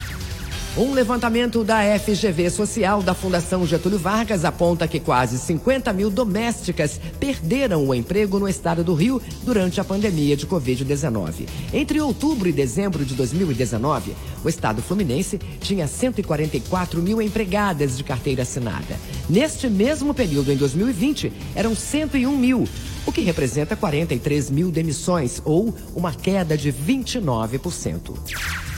• Rádio